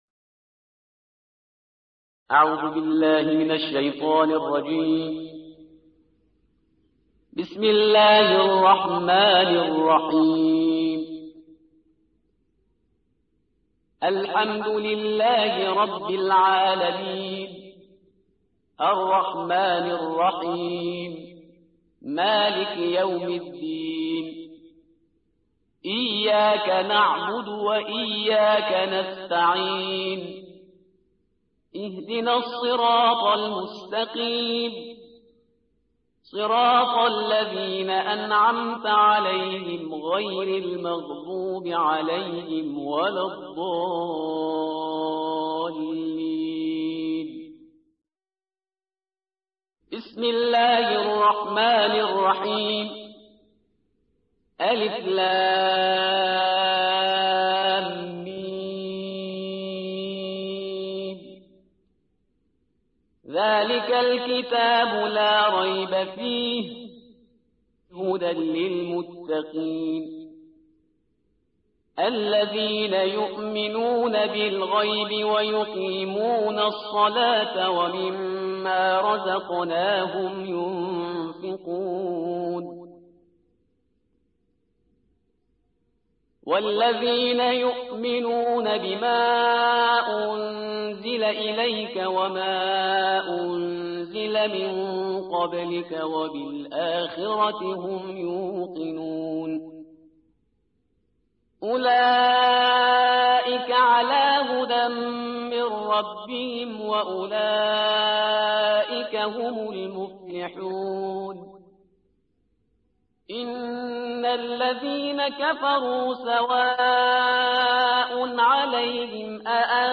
تلاوت ترتیل جزء اول کلام وحی با صدای استاد